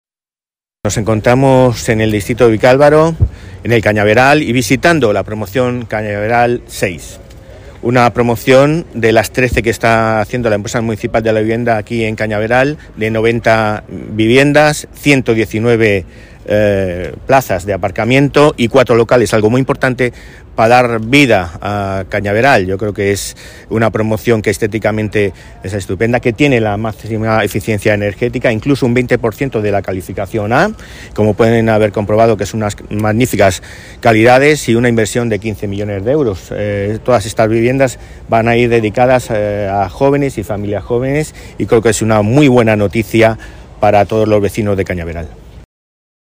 Nueva ventana:Declaraciones del delegado de Políticas de Vivienda y presidente de EMVS Madrid, Álvaro González, durante la visita a Cañaveral 6